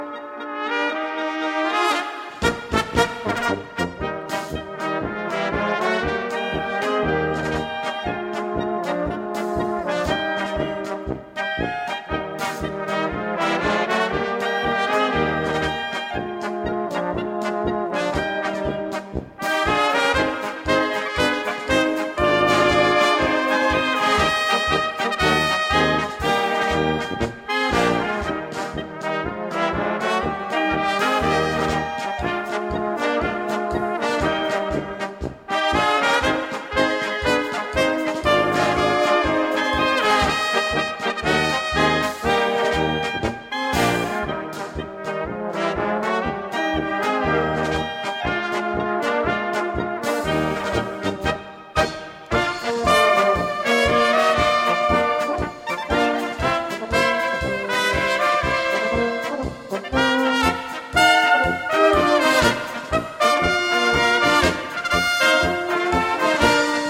Gattung: Polka
3:14 Minuten Besetzung: Blasorchester Zu hören auf